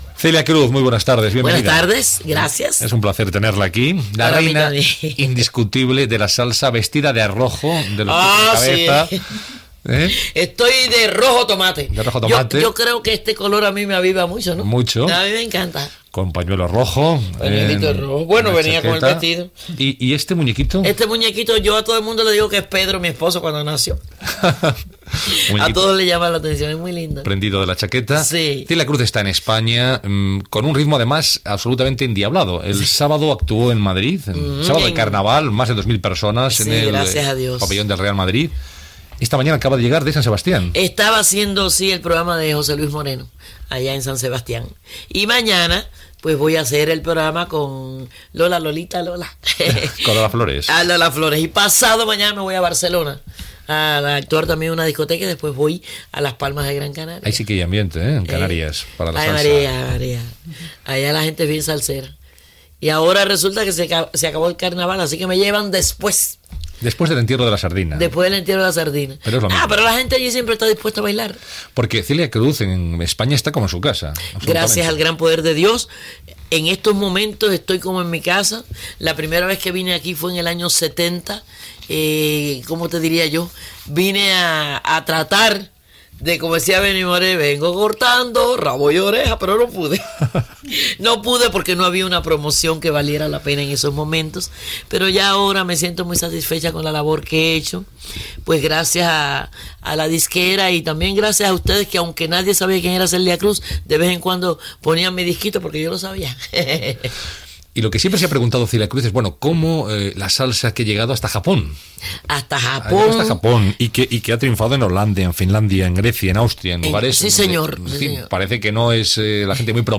Entrevista a la cantant cubana Celia Cruz que està de gira per Espanya